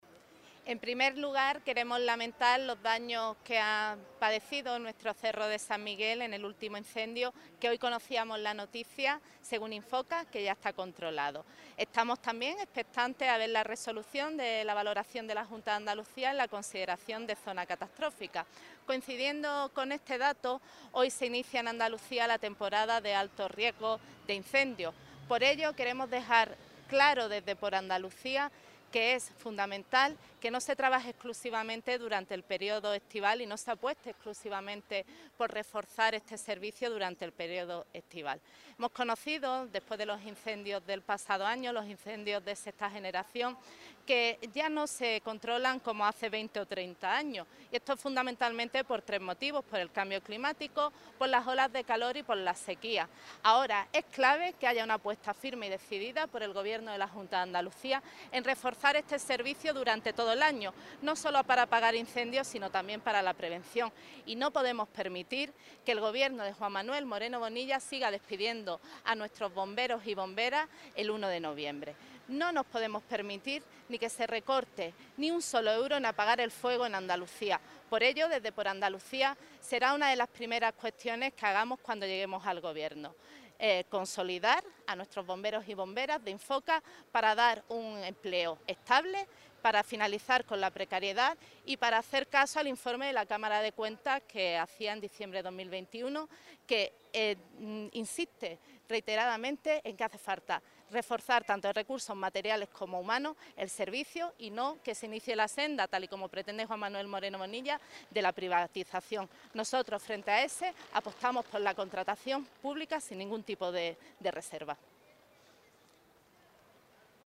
En rueda de prensa, Durán ha advertido que “Andalucía no puede permitirse recortar ni un solo euro en protegerse del fuego” y ha puesto de manifiesto la necesidad de que el próximo Gobierno andaluz haga “una apuesta firme y decidida por reforzar el servicio también durante los meses de invierno, invirtiendo esfuerzos y recursos en redoblar las labores de prevención”.